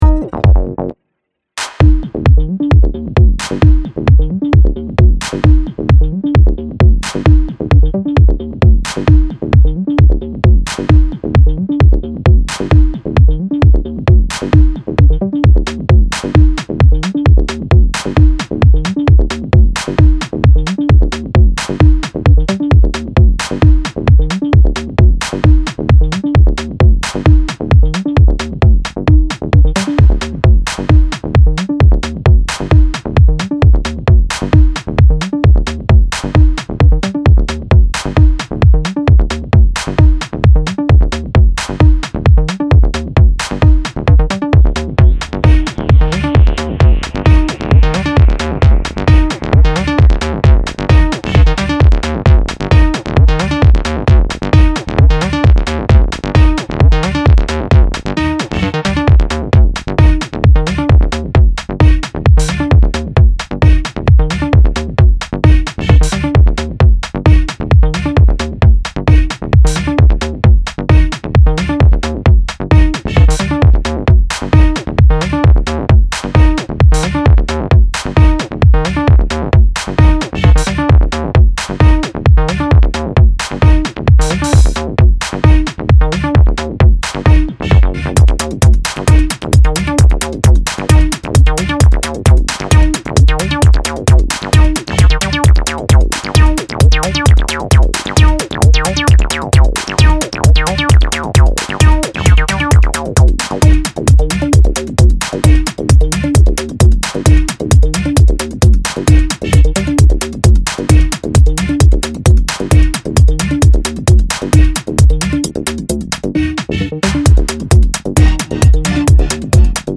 レイヴィーなセットに起用すべき一枚です。